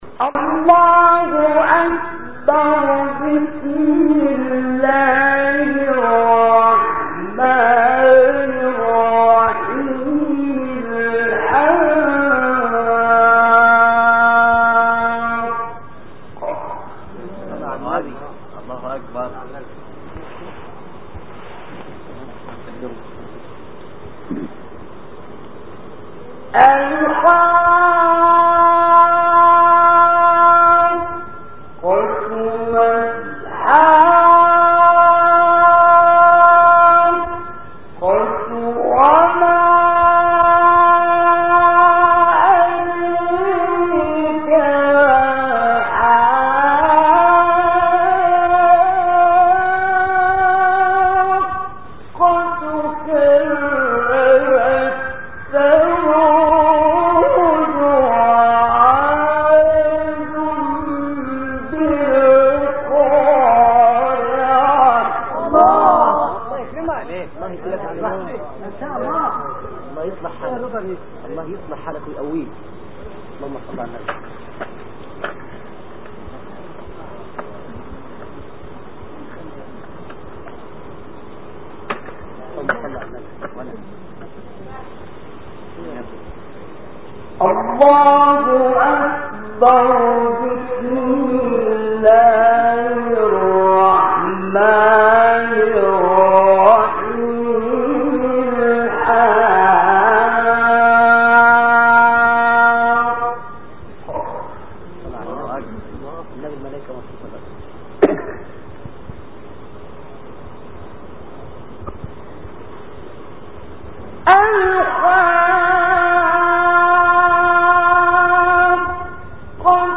تلاوت آیات ابتدایی سوره حاقه توسط استاد حمدی زامل | نغمات قرآن | دانلود تلاوت قرآن